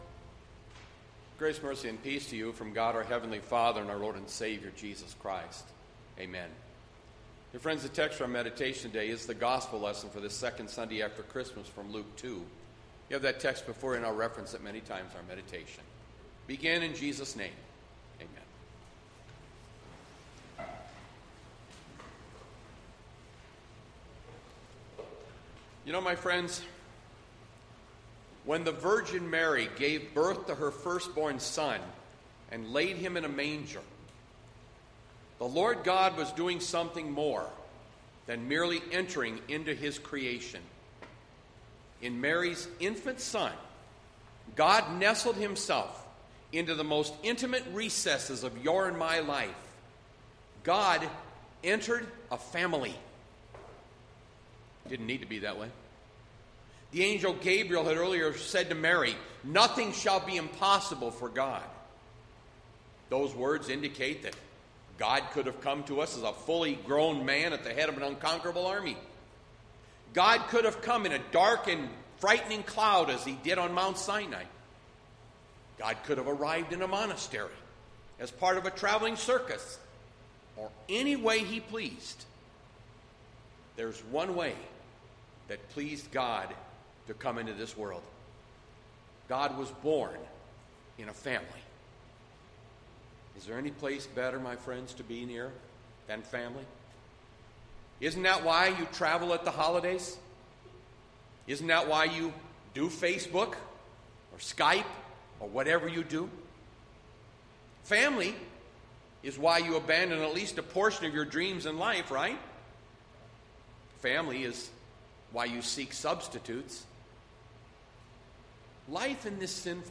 Jan 3, 2021  SERMON ARCHIVE